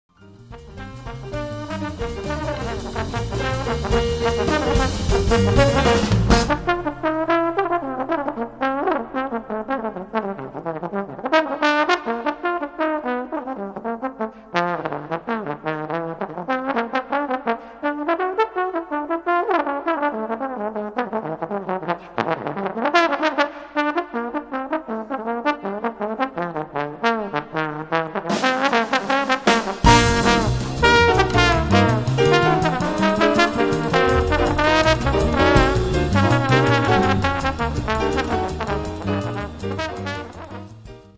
trombone
piano
bass
drums
Recorded at NRK Studio 20
Mixed at Blue Note Studio